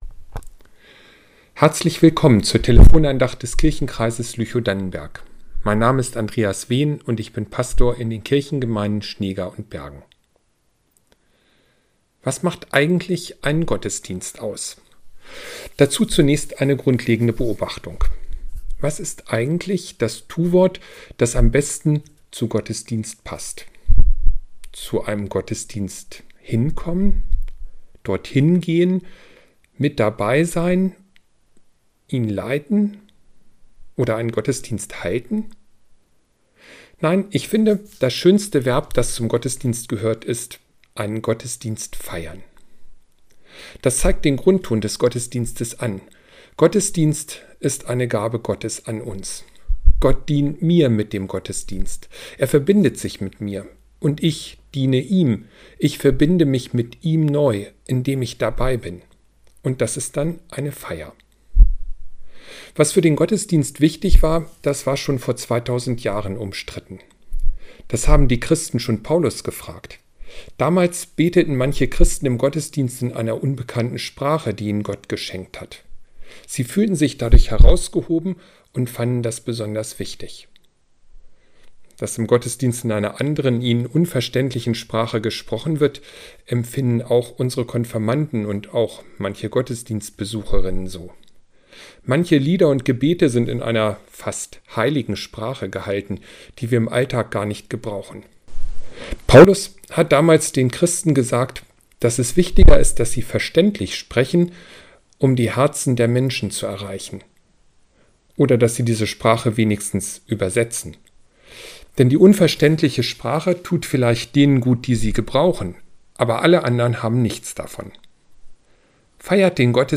Gottesdienst feiern ~ Telefon-Andachten des ev.-luth. Kirchenkreises Lüchow-Dannenberg Podcast